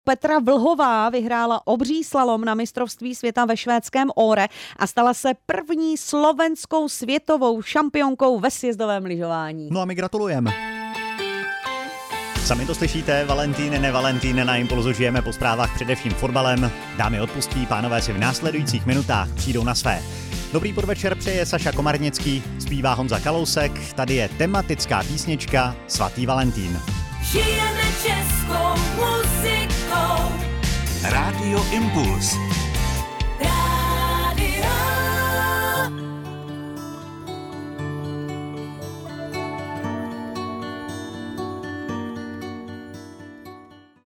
Rádio Impuls – otvírák